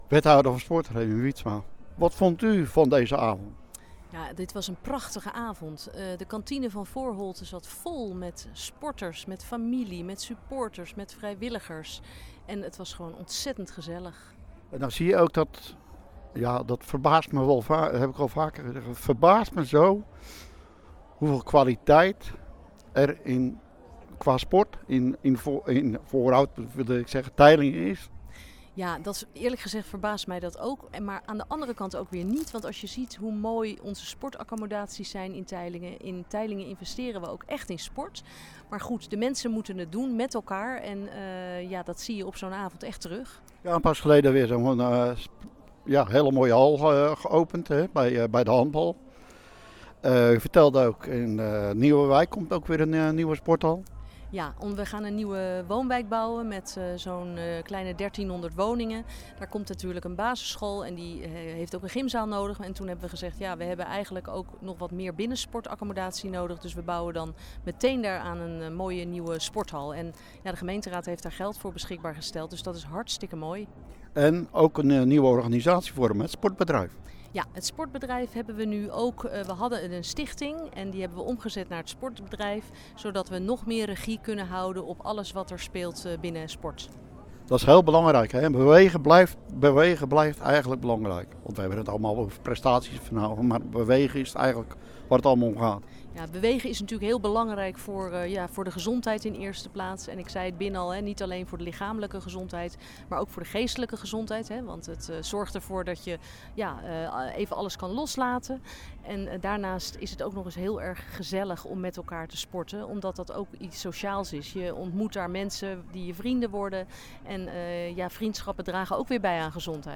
Teylingen – Tijdens het Sportgala Teylingen zijn donderdagavond de jaarlijkse sportprijzen uitgereikt.
Hieronder het radio-interview met wethouder Reny Wietsma: